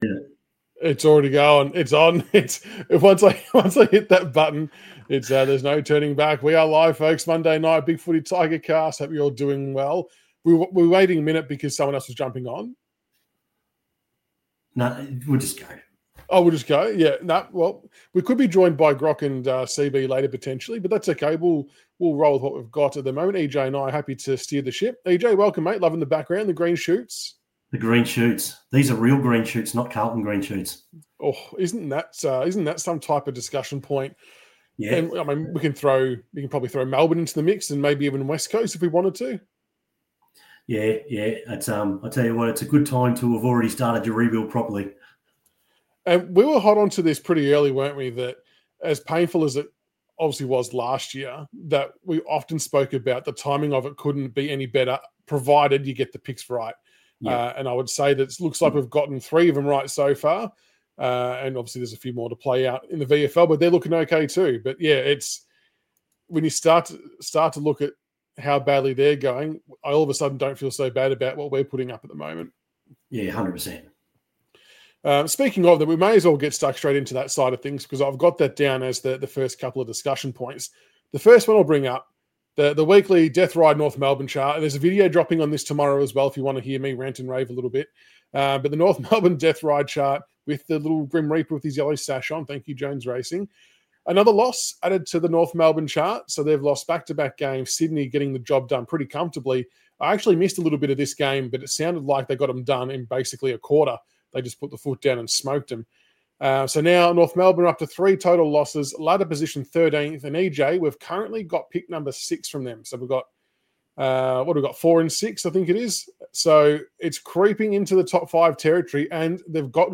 Listen to your favourite Richmond Bigfooty posters discussing all the hot topics surrounding Richmond FC. Reviews, previews, and news are all included as well as popular topics from BigFooty's Richmond forum.
Round5_VSFREMANTLE_LIVEShow.mp3